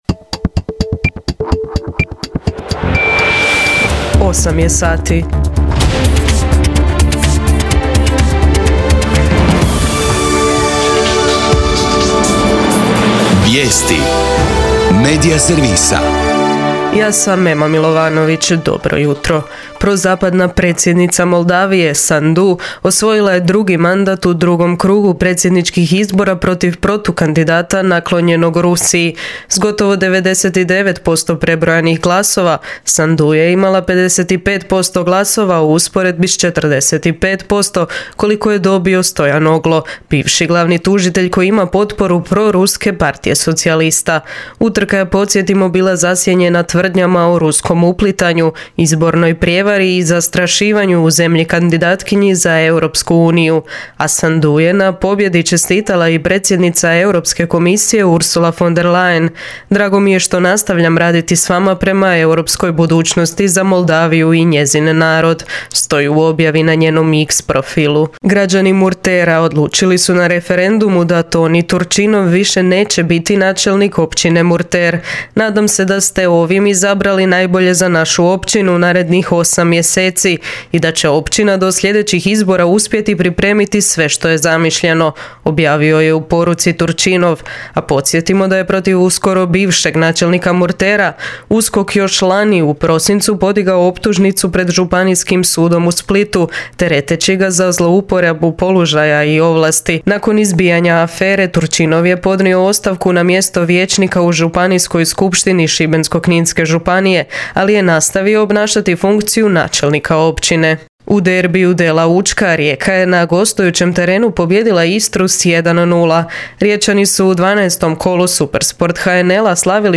VIJESTI U 8